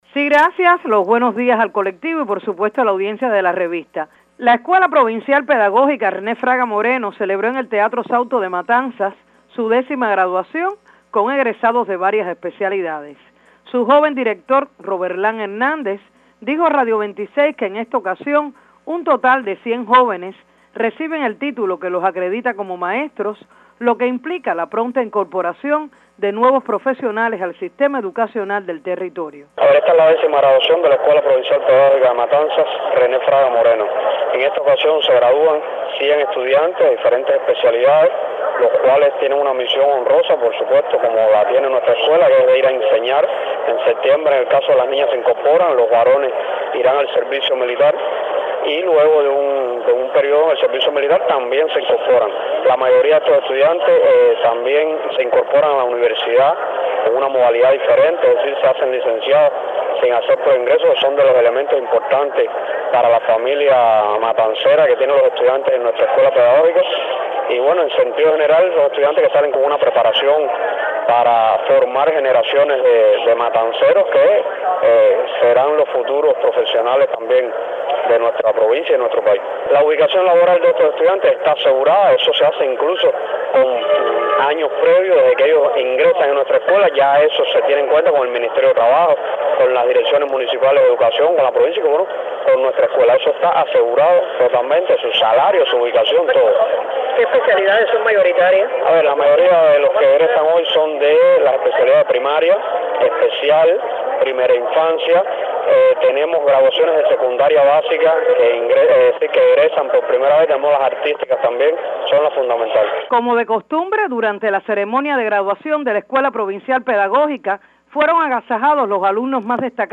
Al concluir la ceremonia de graduación, con sede en el Teatro Sauto, el joven directivo ofreció detalles a Radio 26.